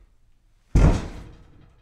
Play, download and share person falling original sound button!!!!
person-falling.mp3